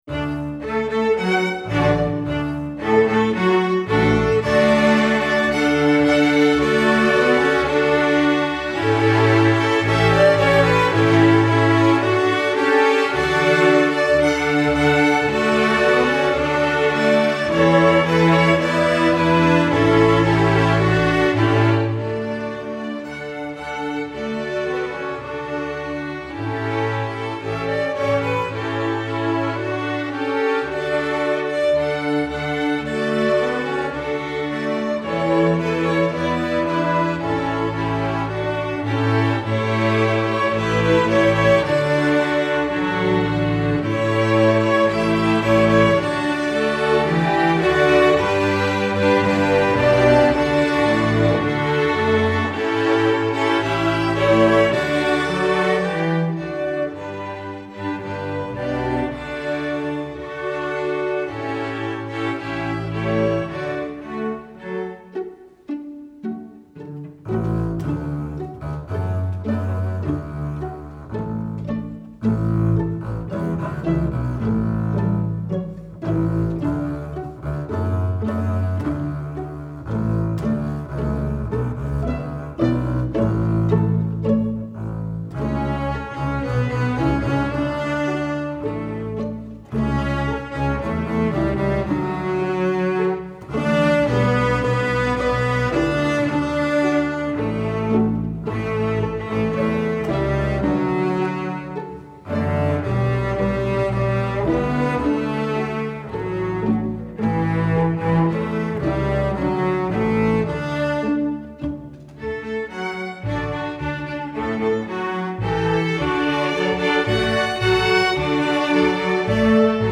masterwork arrangement